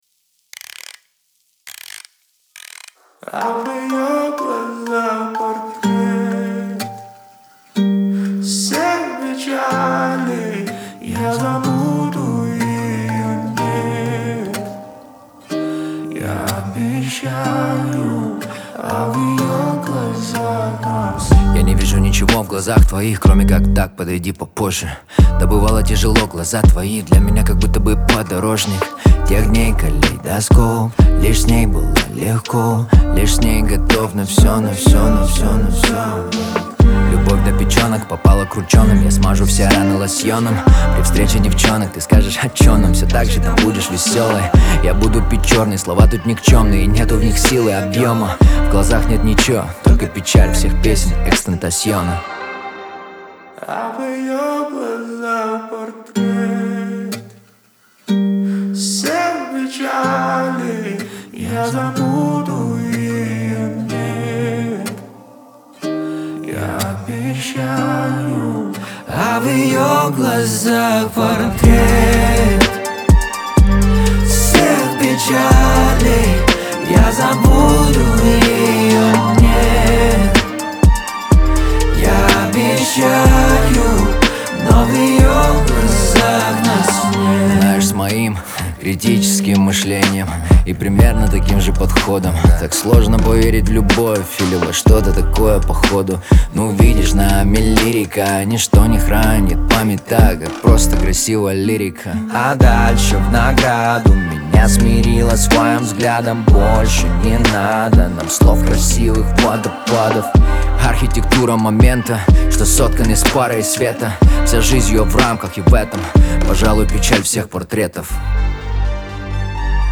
Лирика , эстрада
ХАУС-РЭП